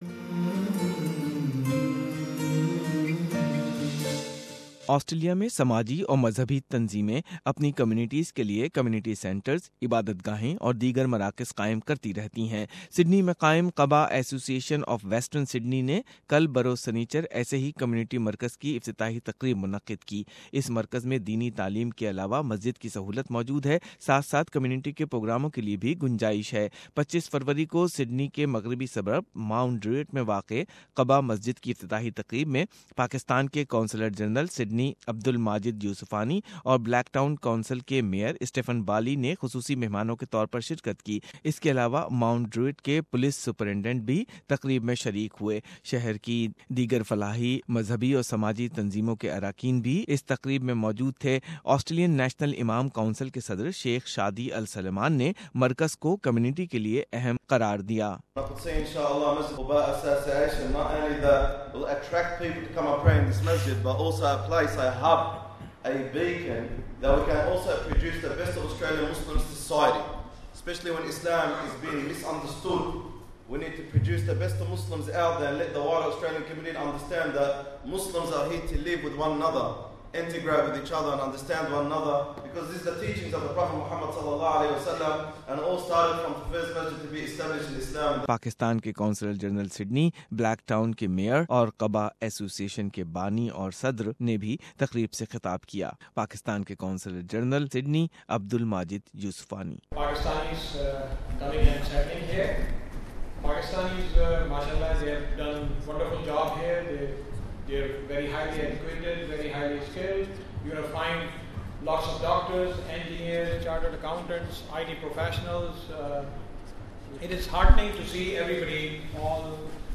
Formal inauguration function - Qubaa Association of Western Sydney